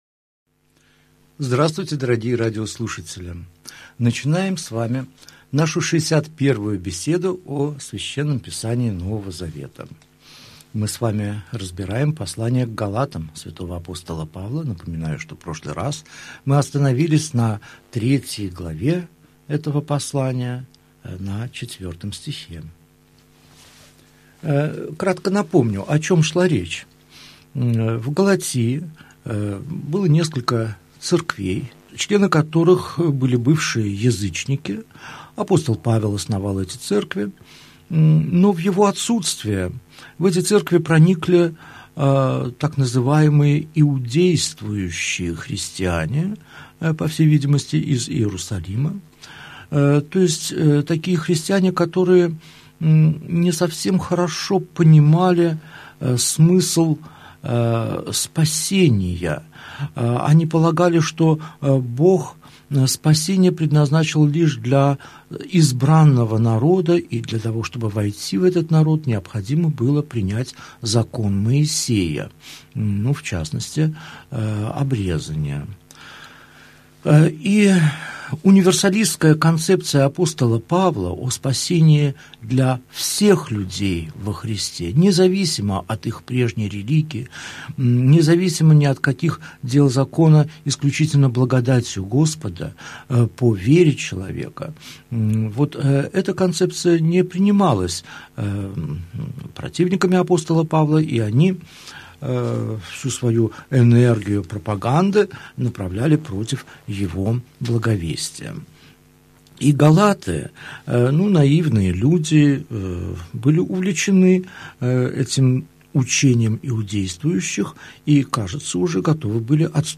Аудиокнига Беседа 61. Послание к Галатам. Глава 3, стихи 5 – 16 | Библиотека аудиокниг